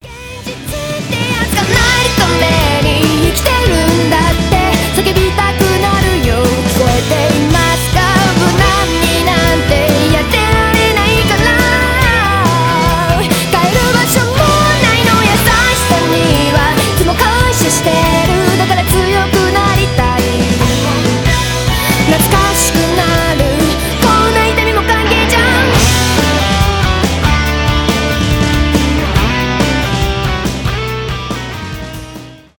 рок , j-pop , зарубежные , аниме , ost , j-rock